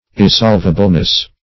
Search Result for " irresolvableness" : The Collaborative International Dictionary of English v.0.48: Irresolvableness \Ir`re*solv"a*ble*ness\, n. The quality or state of being irresolvable; irresolvability.